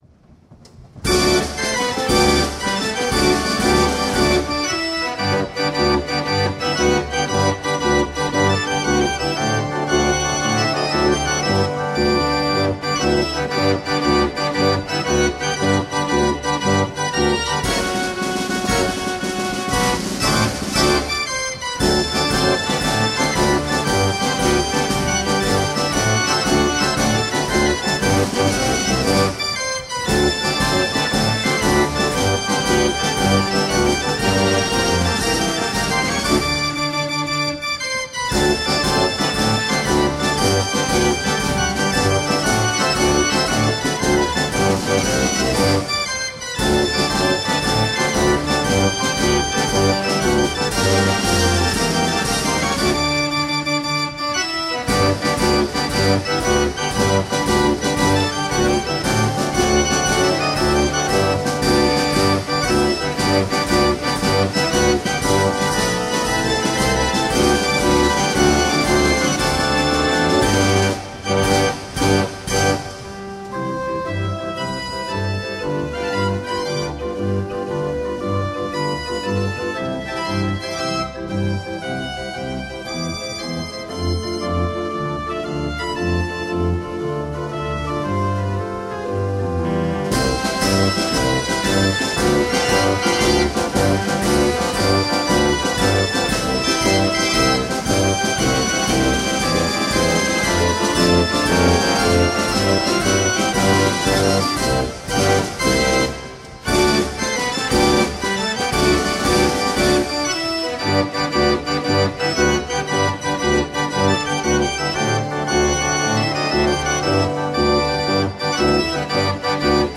Historisches Drehorgeltreffen in Lichtensteig (SG)
Konzert-Notenorgel A.Ruth & Sohn, Modell Nr. 36C
Baujahr: 1926, 79 Claves, Große Konzertorgel in Sonderausstattung.
Kleine Trommel mit 2 separat        ansteuerbaren Schlagstöcken.
Grosse Trommel/Becken